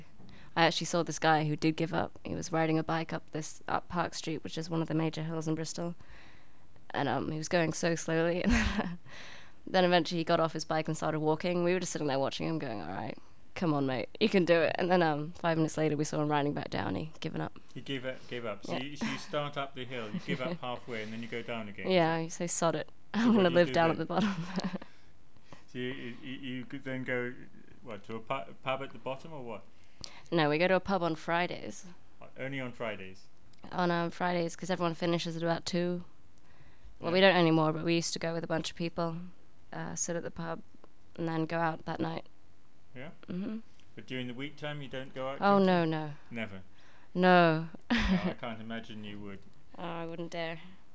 British Female Speaker 1